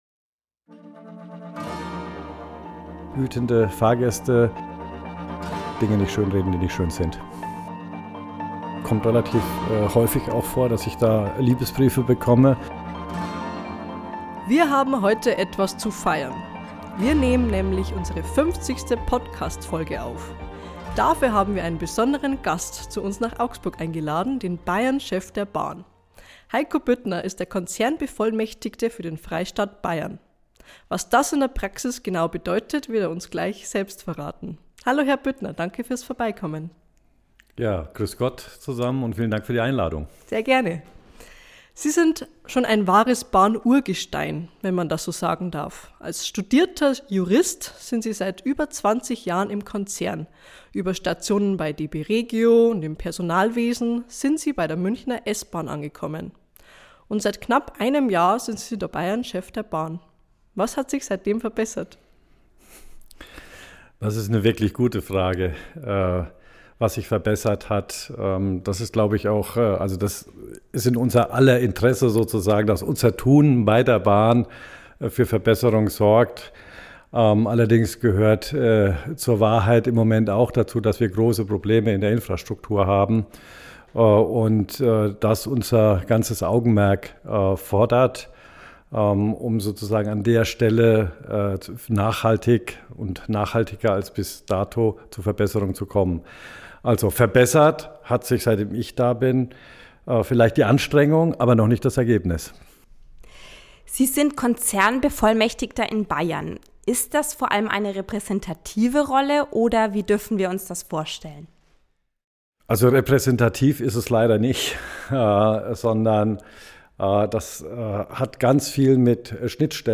Ein Gespräch darüber, was sich seit seinem Amtsantritt verbessert hat, was passiert, wenn er sich als Eisenbahner zu erkennen gibt und wie er mit Kritik umgeht.